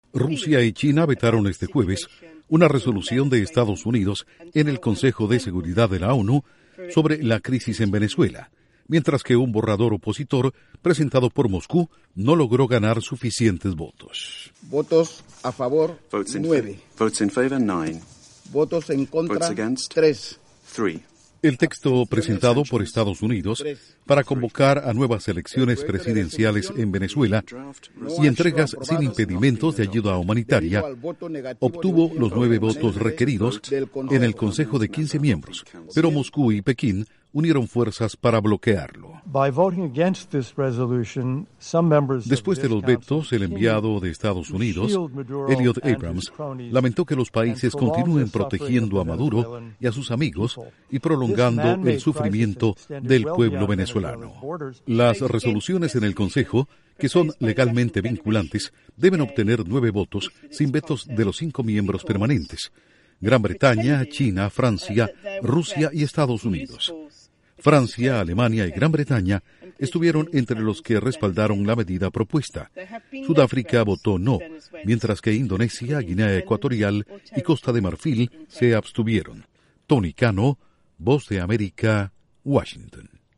Duración: 1:23 Incluye audio de Elliott Abrahms/Representante de EE.UU.